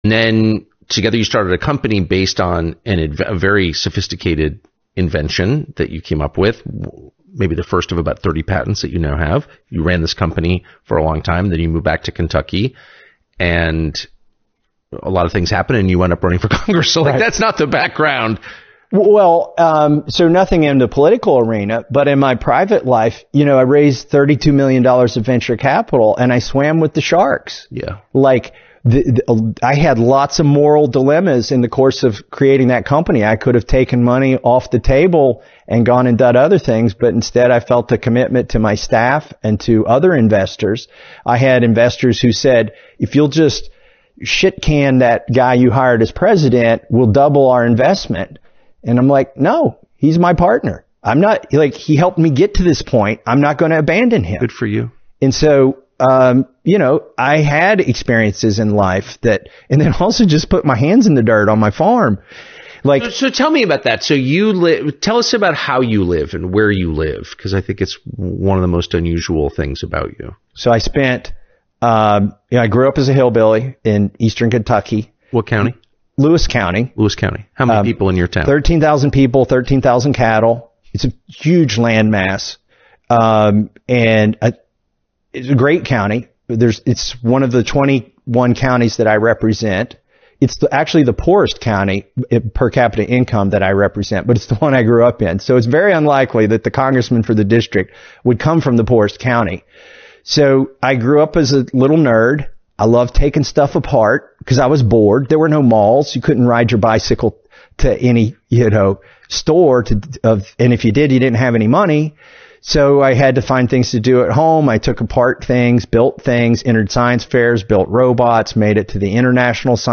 Thomas Massie on the Tucker Carlson Network